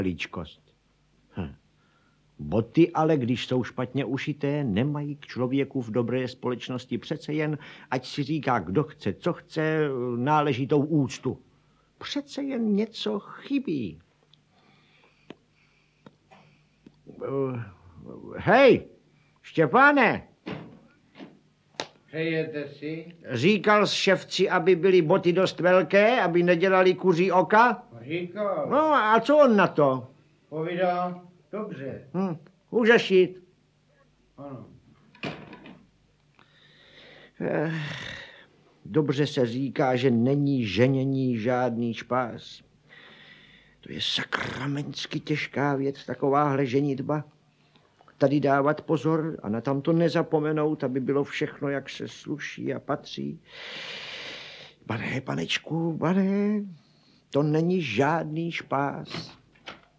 Audiobook
Audiobooks » Short Stories
Read: František Filipovský